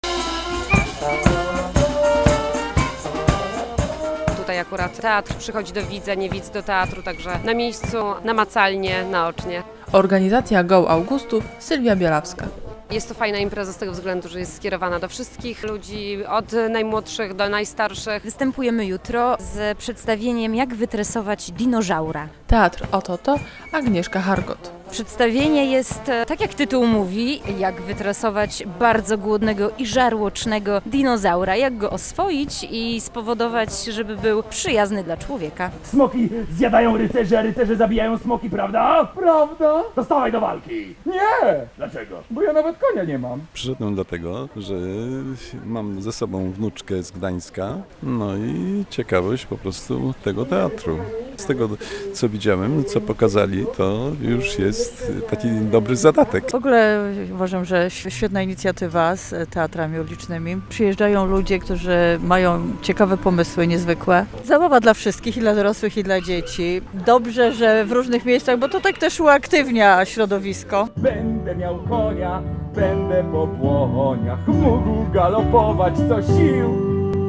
Festiwal Go Teatr w Augustowie - relacja